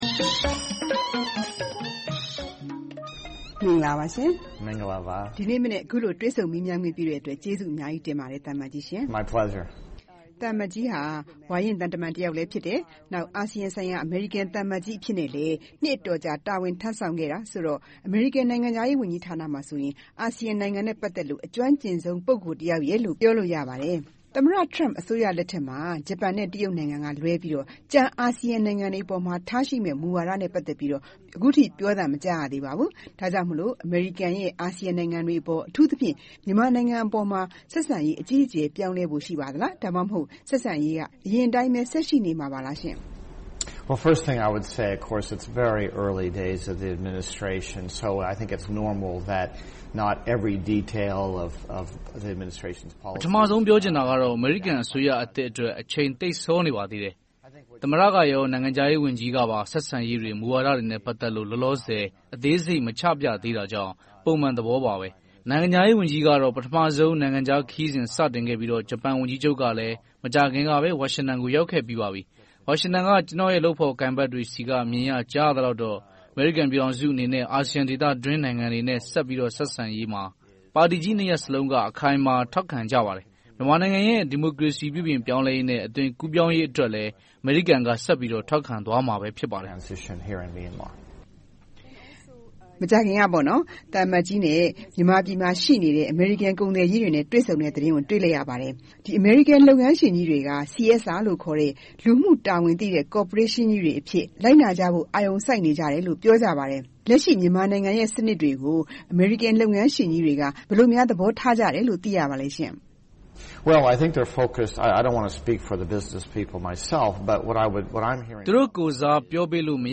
သီးသန့်တွေ့ဆုံမေးမြန်းထားပါတယ်။